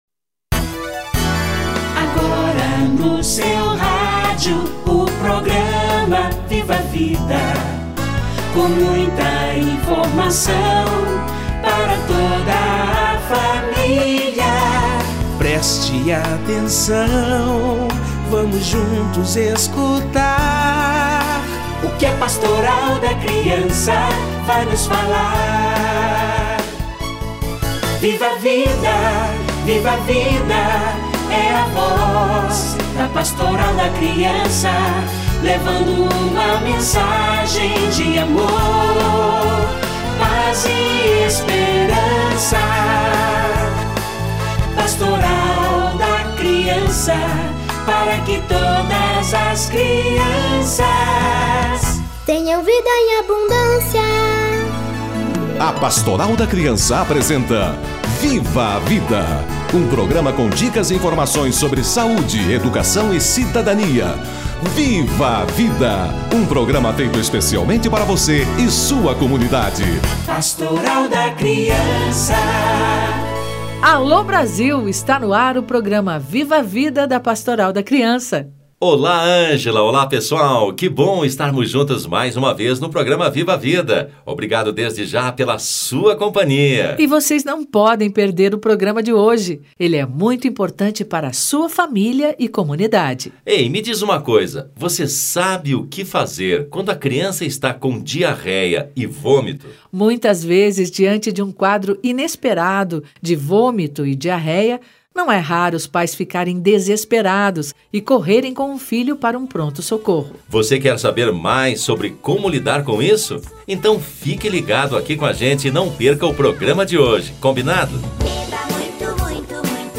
Vômitos e diarreias - Entrevista